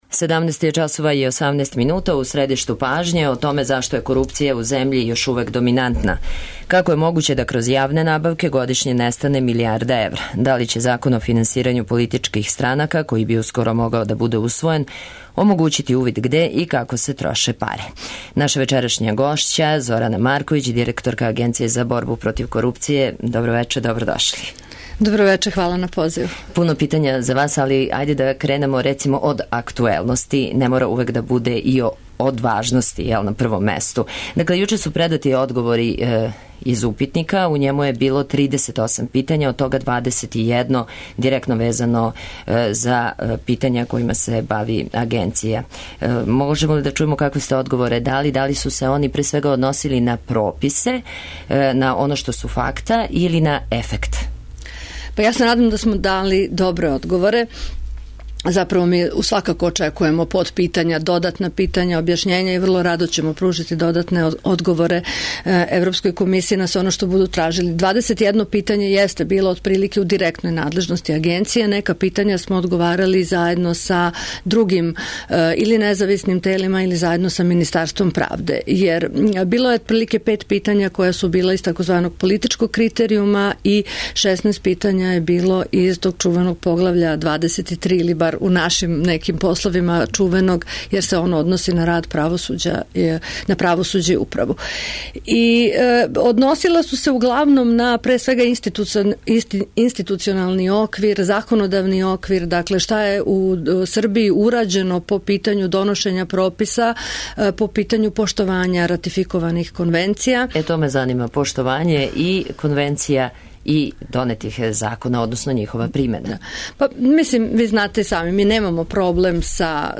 Гост емисије је Зорана Марковић, директорка Агенције за борбу против корупције.